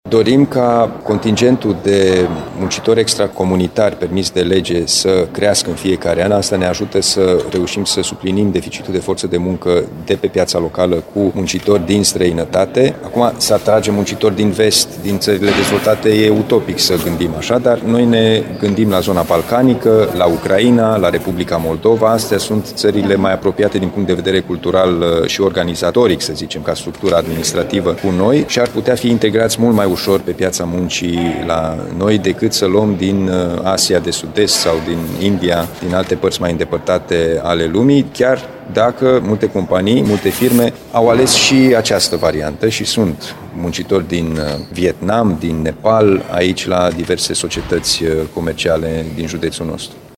În condițiile în care șomajul este foarte redus, aproximativ unu la sută, aceste persoane ar putea fi integrate în companiile care duc lipsă de angajați, spune președintele Consiliului Județean Timiș, Alin Nica: